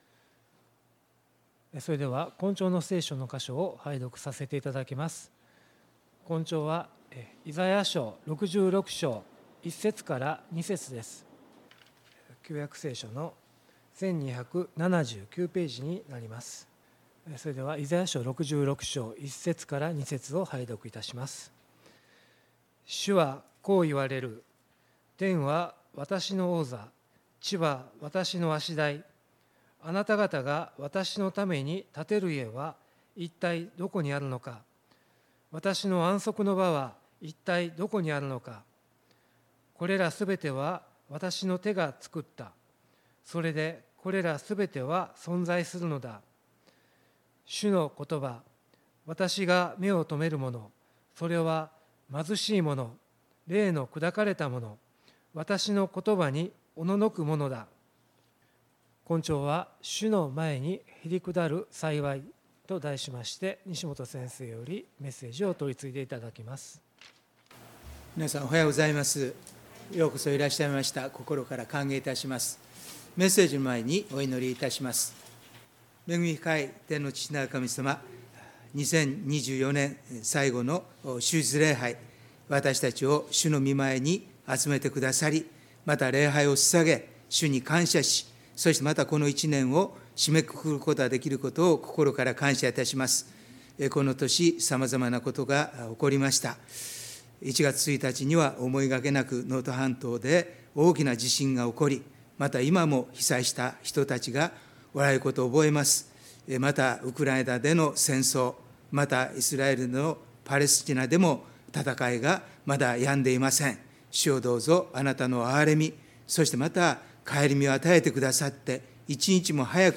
礼拝メッセージ「主の前にへりくだる幸い」│日本イエス・キリスト教団 柏 原 教 会